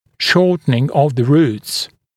[‘ʃɔːtnɪŋ əv ðə ruːts][‘шо:тнин ов зэ ру:тс]уменьшение длины корней